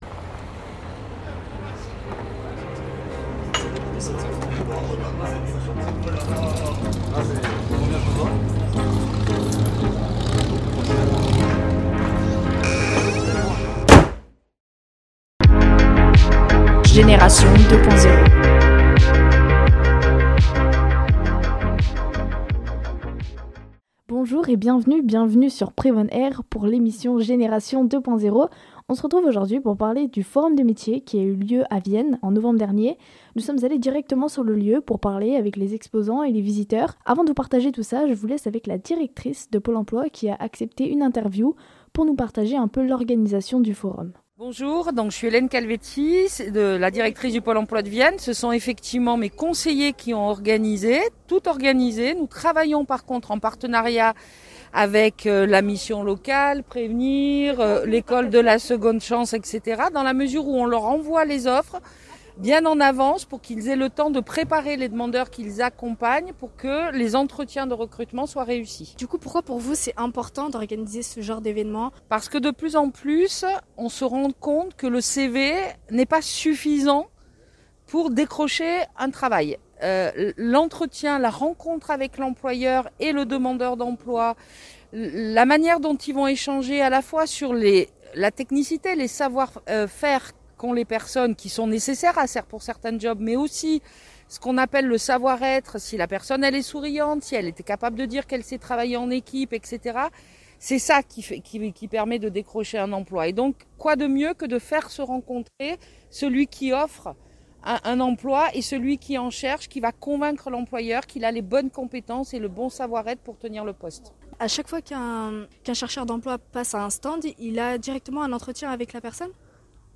Nous sommes allés au forum de l’emplo i qui a eu lieu à Vienne en novembre 2021.
Nous sommes allés à la rencontre de certains exposants.